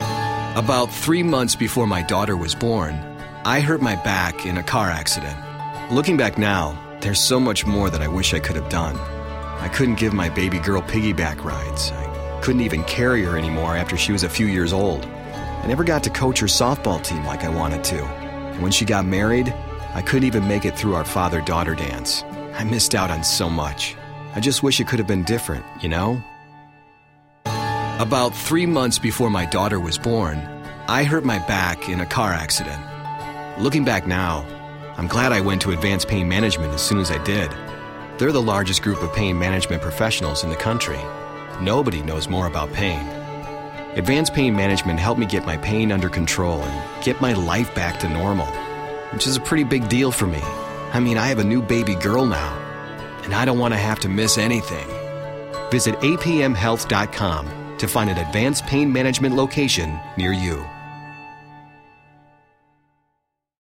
It is essentially two spots in a row, first part telling the story of a man reflecting on all he has missed out on in life after a back injury. The second portion of the spot begins the same way, with the same music, momentarily fooling the listener into thinking the radio station accidentally played the same commercial two times in a row. But the story and music begin to change in subtle ways as he talks about his experience at Advanced Pain Management.